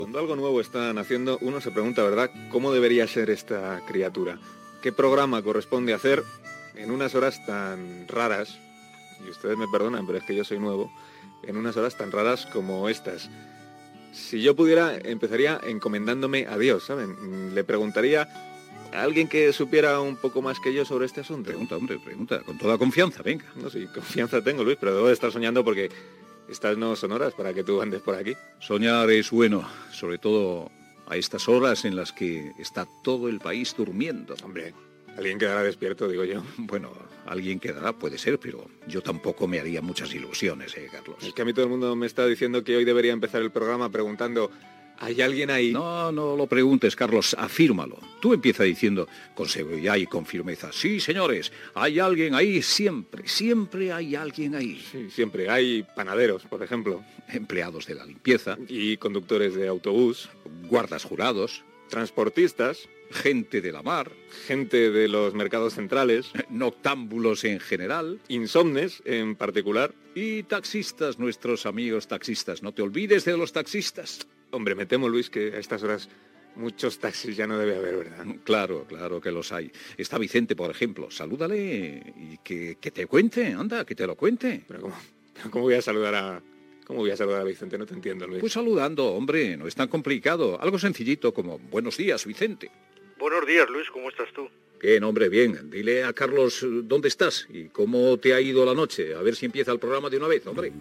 Conversa de Carlos Alsina amb Luis del Olmo sobre els possibles oients de la ràdio a primera hora del matí i notícia sobre el projecte genoma
Info-entreteniment